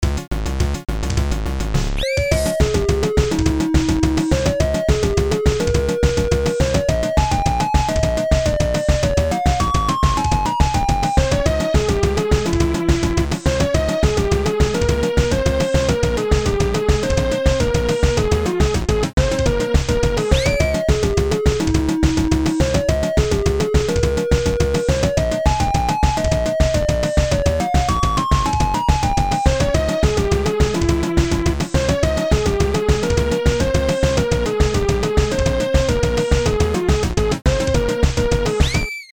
Game Music
synth